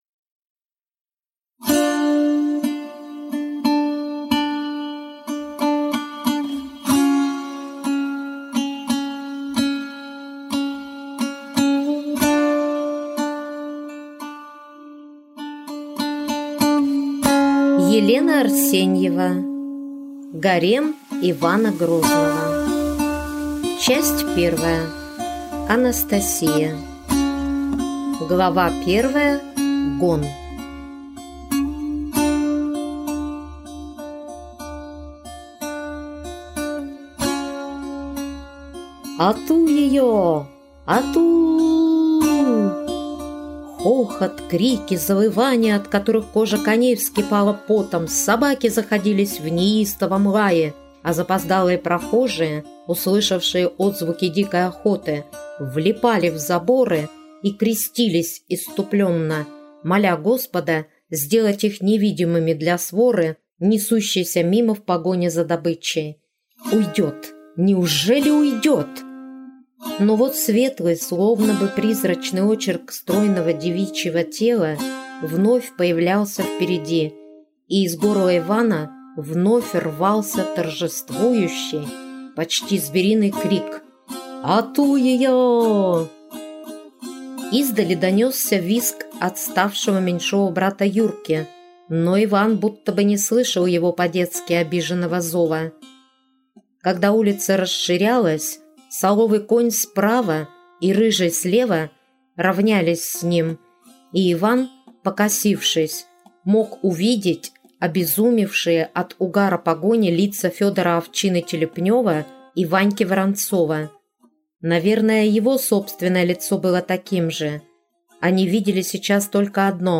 Аудиокнига Гарем Ивана Грозного | Библиотека аудиокниг